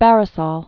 (bărĭ-sôl, bŭrĭ-säl)